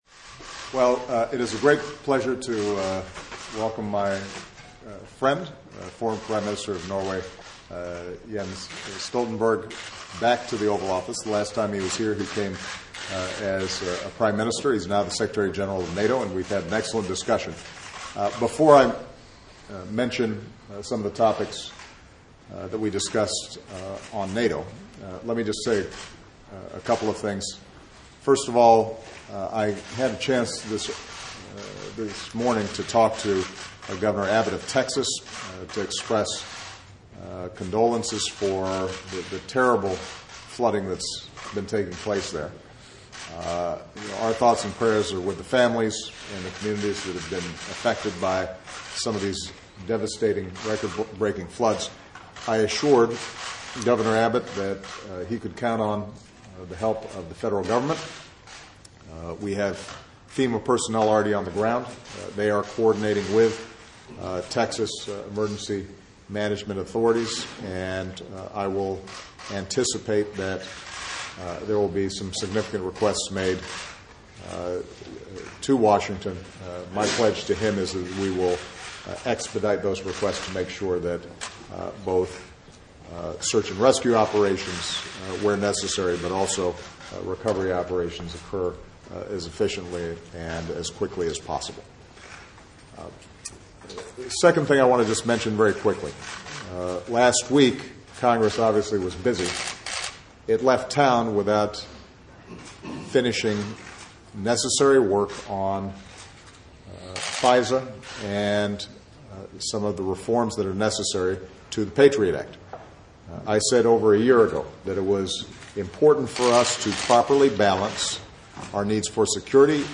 Joint press statements
by US President Barack Obama and NATO Secretary General Jens Stoltenberg at the White House